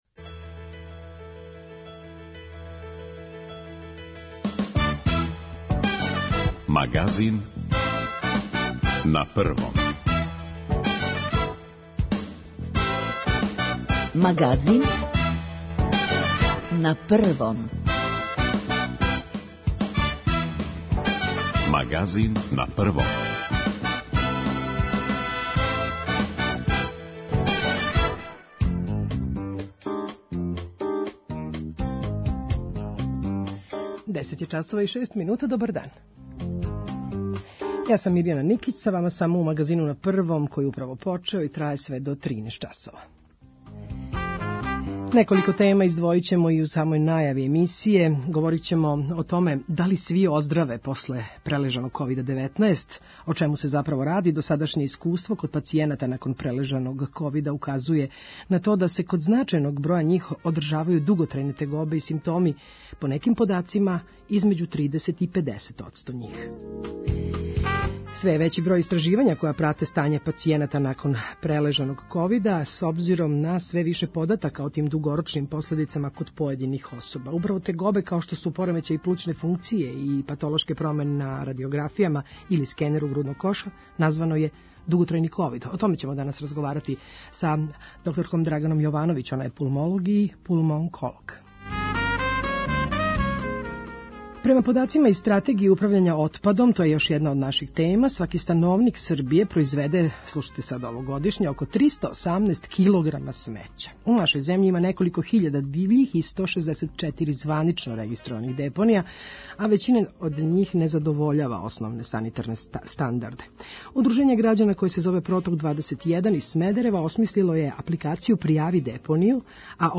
Аудио подкаст Радио Београд 1